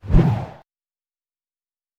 sfx_swooshing.wav